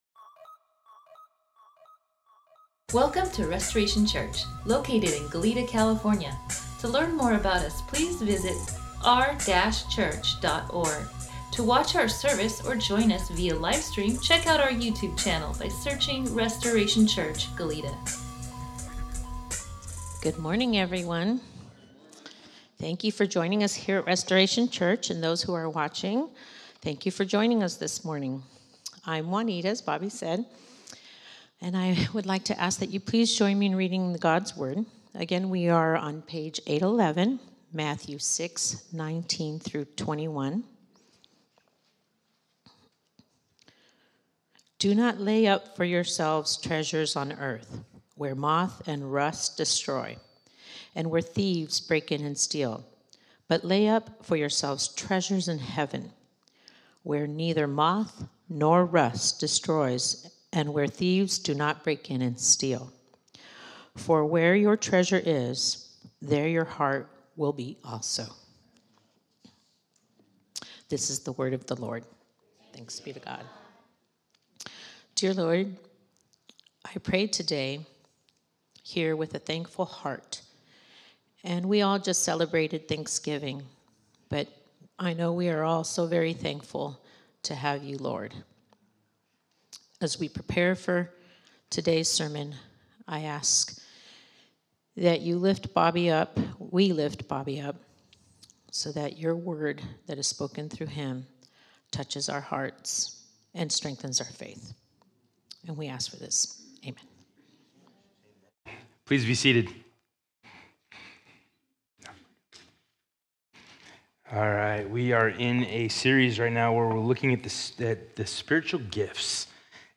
Sermon NotesDownload Welcome to Restoration Church, Goleta!